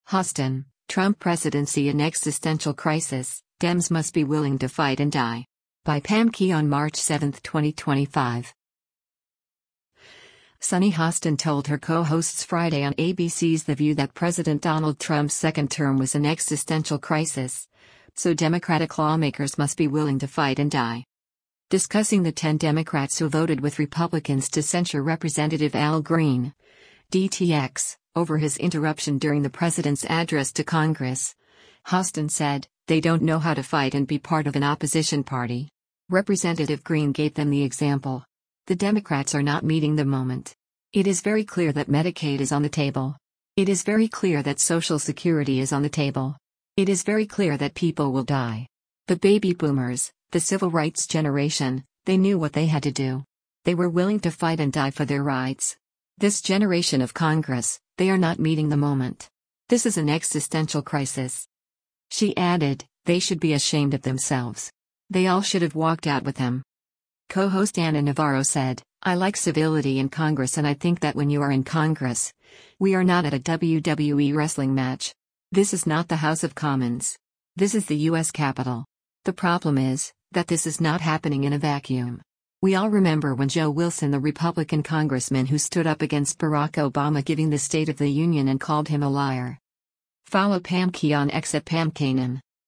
Sunny Hostin told her co-hosts Friday on ABC’s “The View” that President Donald Trump’s second term was an “existential crisis,” so Democratic lawmakers must be willing to “fight and die.”